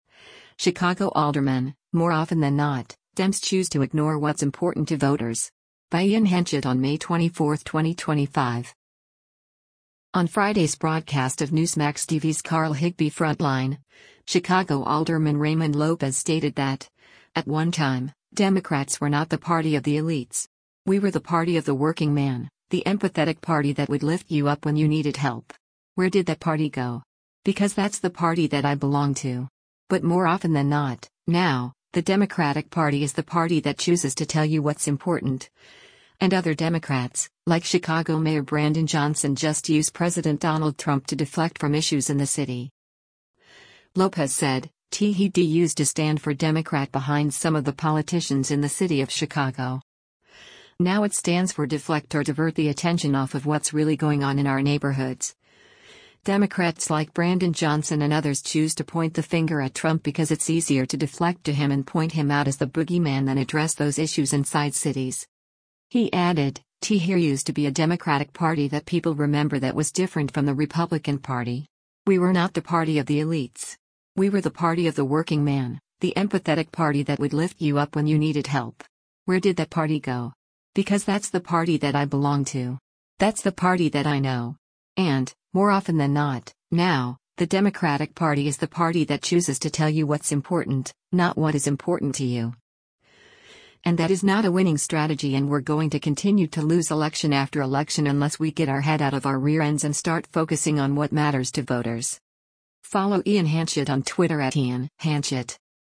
On Friday’s broadcast of Newsmax TV’s “Carl Higbie Frontline,” Chicago Alderman Raymond Lopez stated that, at one time, Democrats “were not the party of the elites.